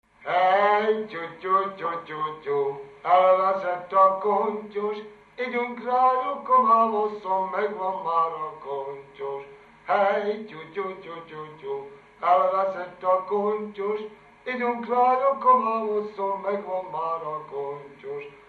Dunántúl - Zala vm. - Nova
ének
Stílus: 7. Régies kisambitusú dallamok
Kadencia: (1) 1